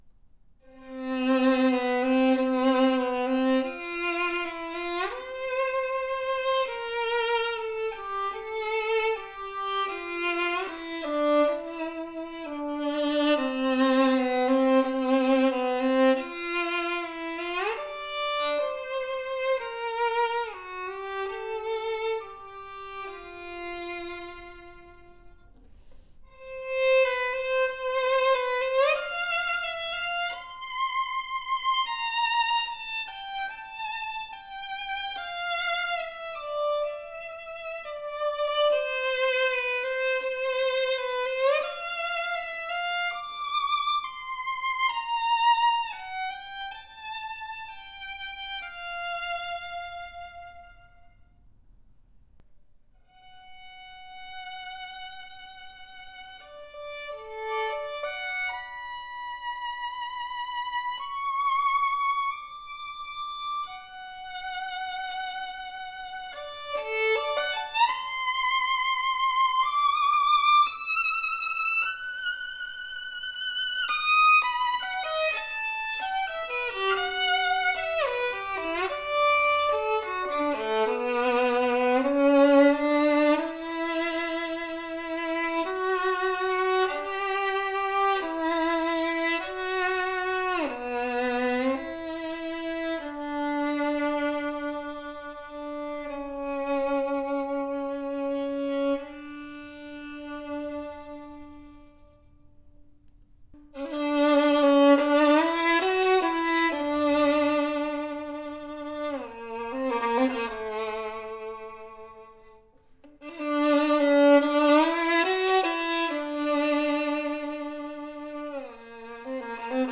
• Classic Italian antique golden brown varnish, tone oriented.
• Pro set up with Tonica string, Aubert bridge.
A RARE fine sounding violin at this price range, special edition made after the Gagliano pattern, that delivers a superior WARM SWEET and PROJECTIVE tone, more tone than you would expect at this price range!! Exquisite antique reddish brown varnish, full and extra higher arching creates a powerful tone with deep and bold projection. A vibrant, mellow sounding violin that built with seriousness, our most affordable instrument that represents super value for violin players.